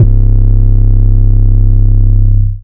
808s
808 1 {C} [ not nice ].wav